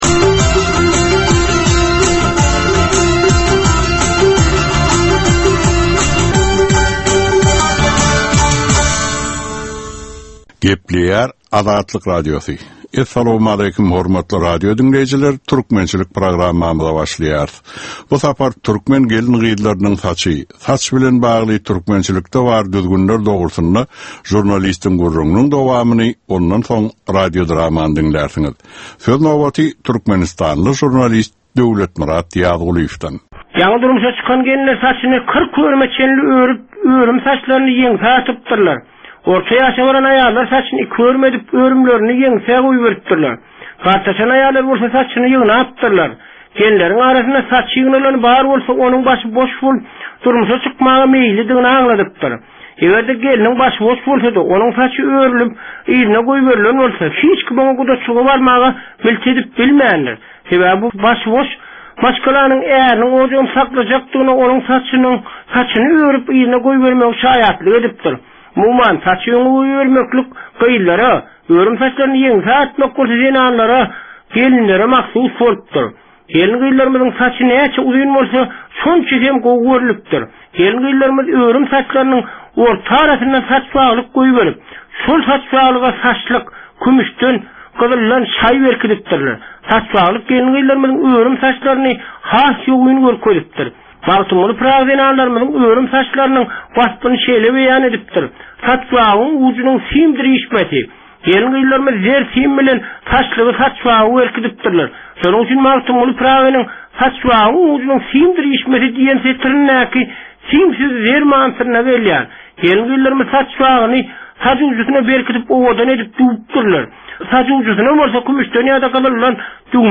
Türkmen halkynyn däp-dessurlary we olaryn dürli meseleleri barada 10 minutlyk ýörite geplesik. Bu programmanyn dowamynda türkmen jemgyýetinin su günki meseleleri barada taýýarlanylan radio-dramalar hem efire berilýär.